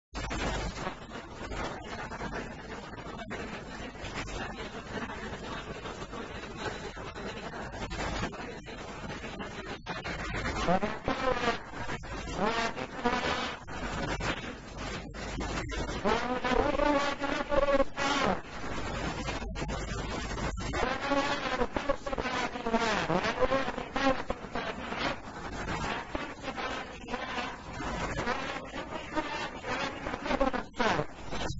| فتاوى الشيخ مقبل بن هادي الوادعي رحمه الله